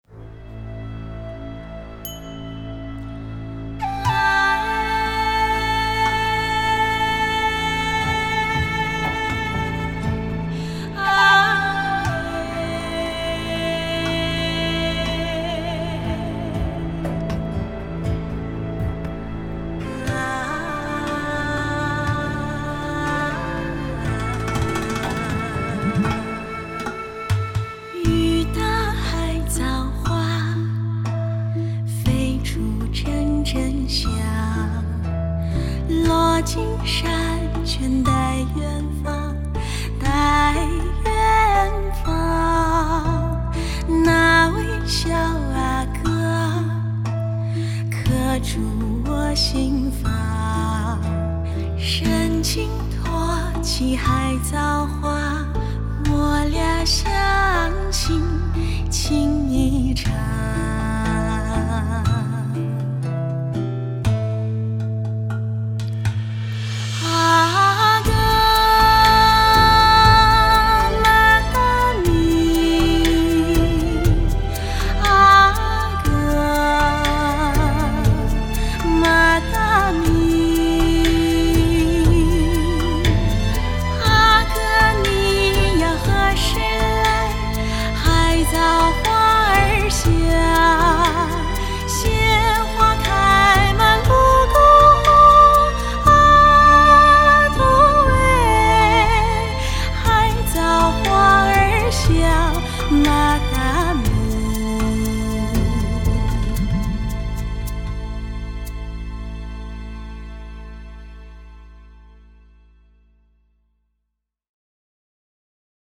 剧中配乐录音乐手：
独奏大提琴
独奏小提琴
葫芦丝、竹笛
长笛
人声配唱
中提琴
低音提琴
吉他
打击乐及小打
Didgeridoo